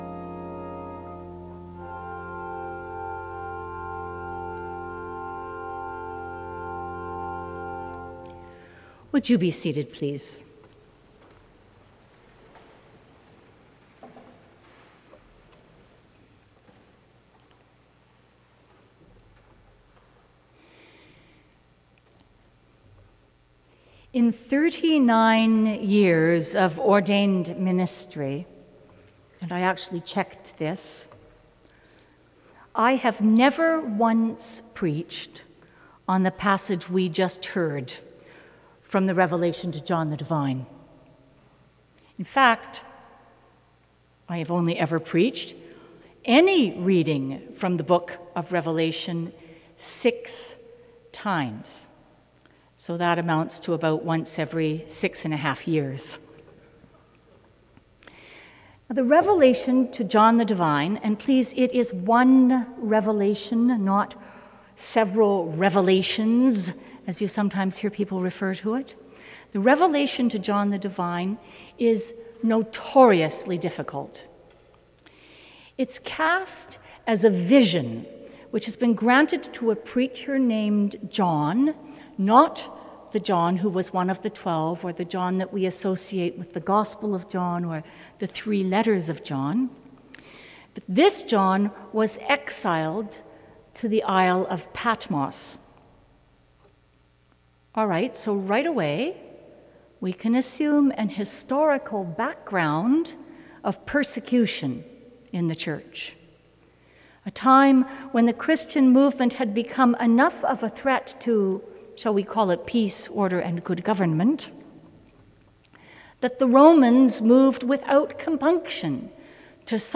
Sermon: 11.00 a.m. service